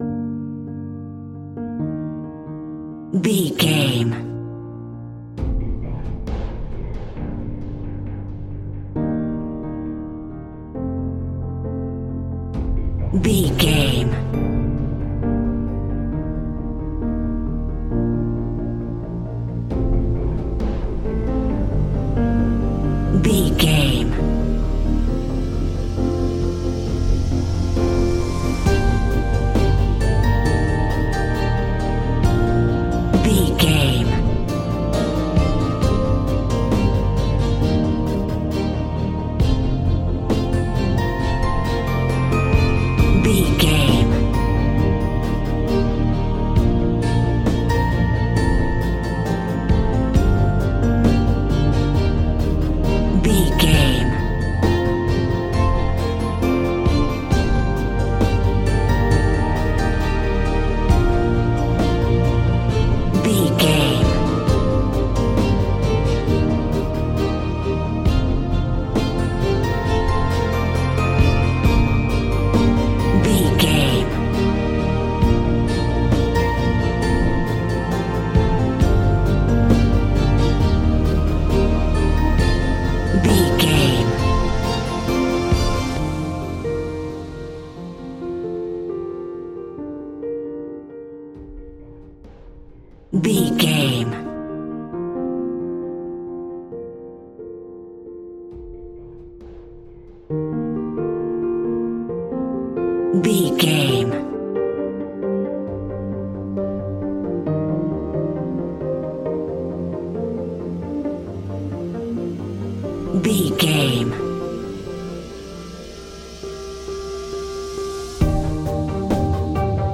Aeolian/Minor
dramatic
epic
strings
percussion
synthesiser
brass
violin
cello
double bass